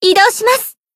贡献 ） 分类:蔚蓝档案语音 协议:Copyright 您不可以覆盖此文件。
BA_V_Tomoe_Battle_Tacticalaction_1.ogg